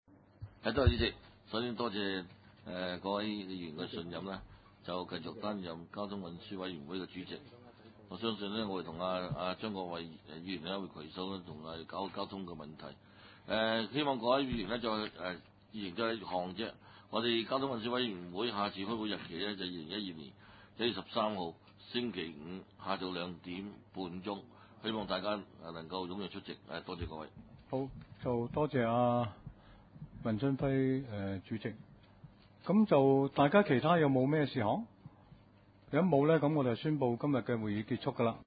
委员会会议的录音记录